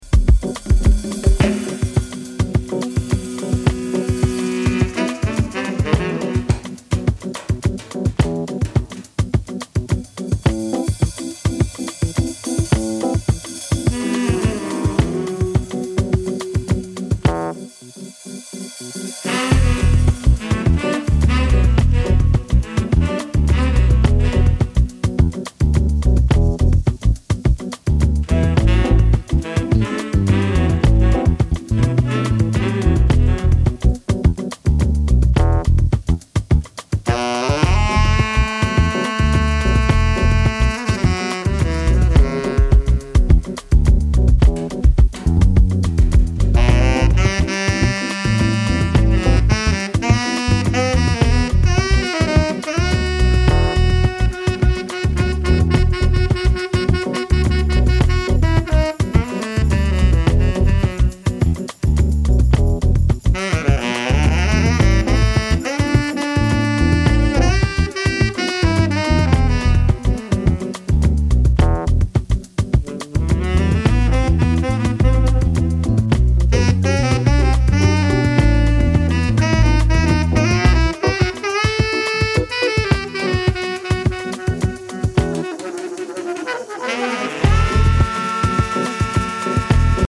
ジャンル(スタイル) JAZZ FUNK HOUSE / HOUSE